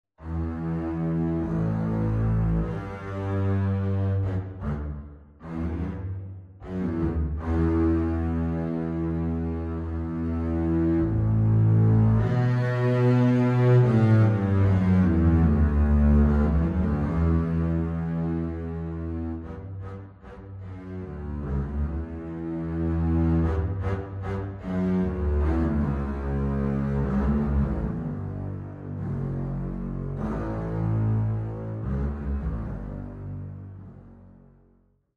Two pieces for unaccompanied Double Bass
Double Bass Solo